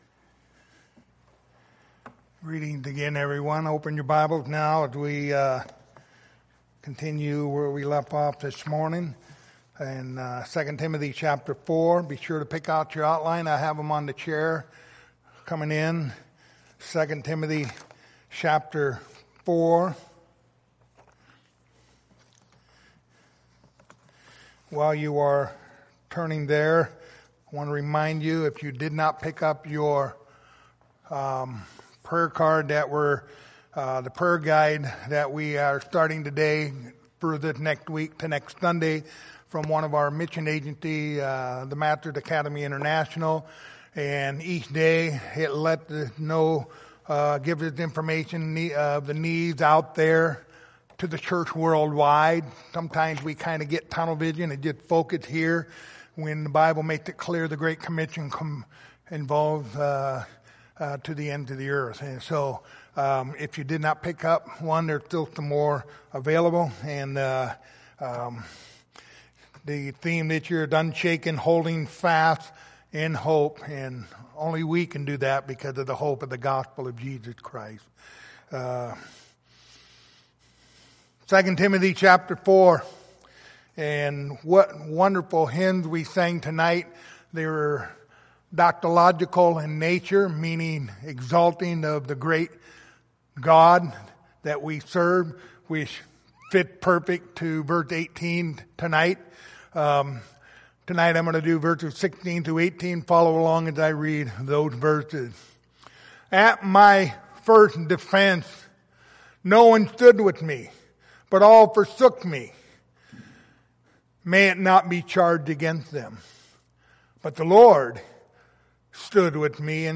Passage: 2 Timothy 4:16-18 Service Type: Sunday Evening